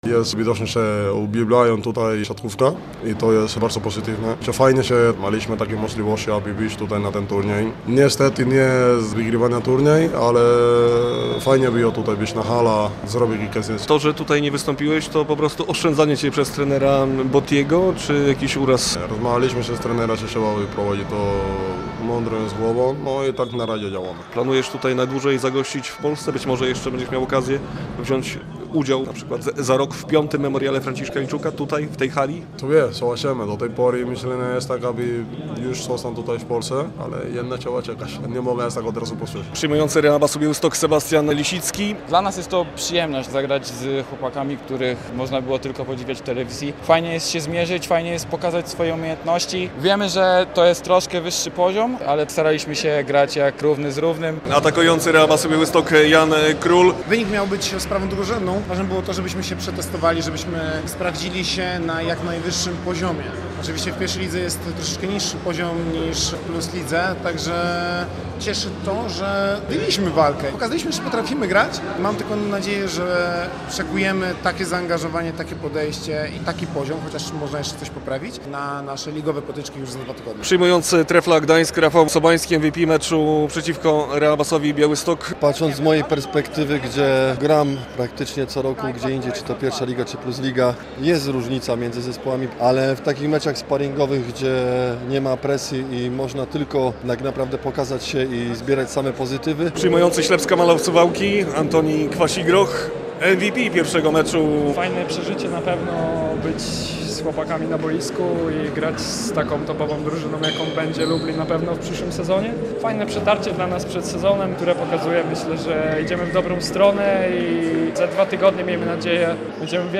Podsumowanie siatkarskiego święta - w relacji